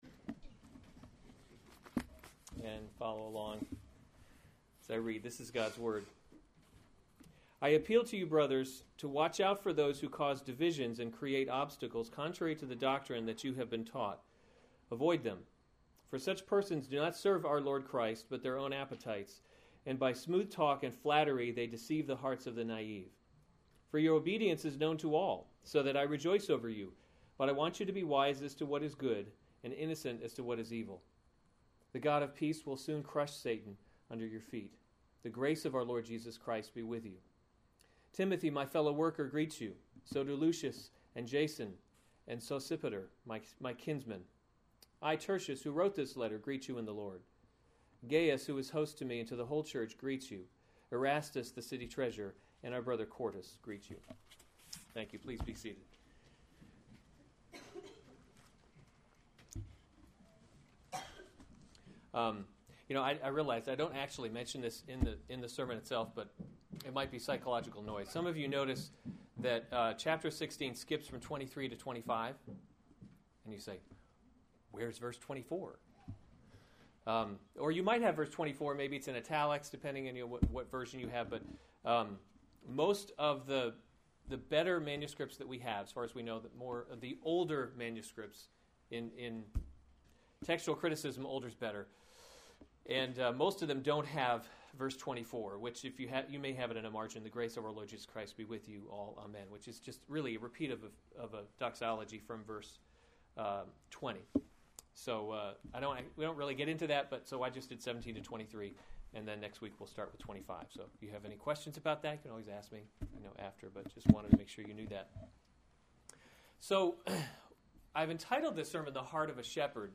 May 30, 2015 Romans – God’s Glory in Salvation series Weekly Sunday Service Save/Download this sermon Romans 16:17-23 Other sermons from Romans Final Instructions and Greetings 17 I appeal to you, […]